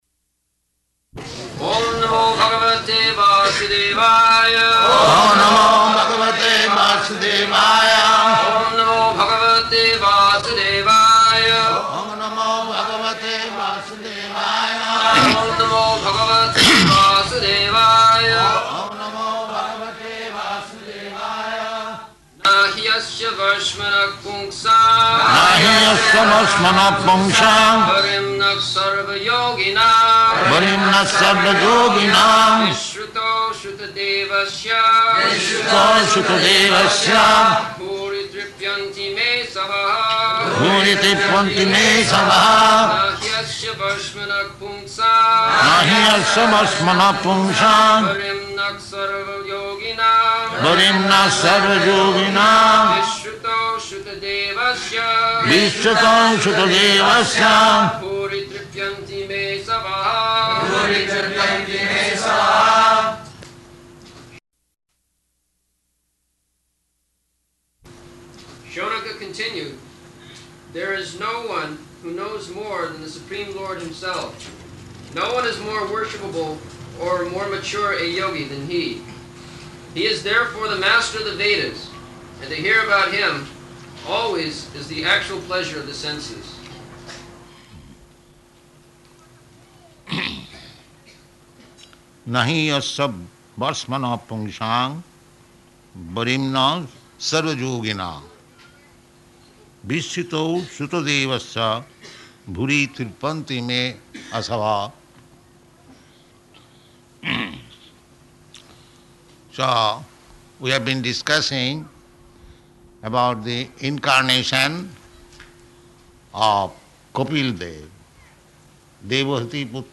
November 2nd 1974 Location: Bombay Audio file